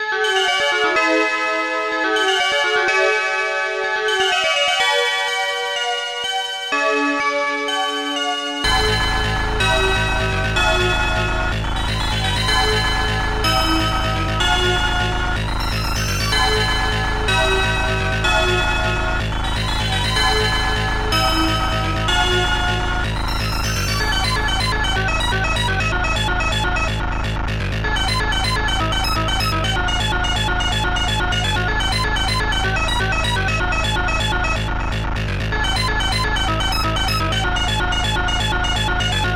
Protracker Module
ST-10:d-50stringbell ST-10:synth-9 ST-10:d-50synbass2